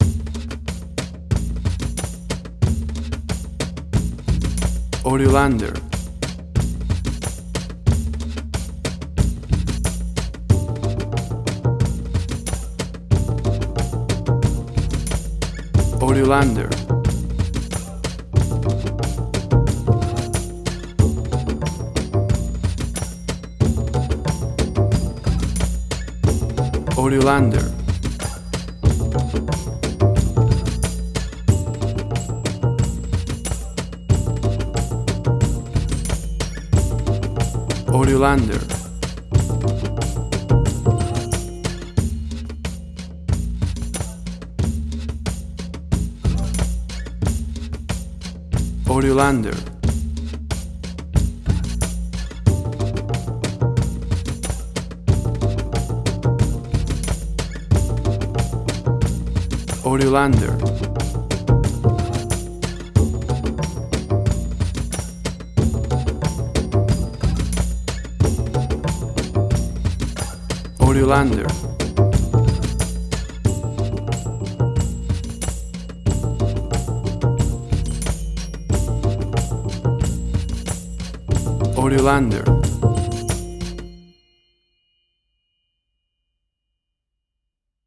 WAV Sample Rate: 24-Bit stereo, 48.0 kHz
Tempo (BPM): 92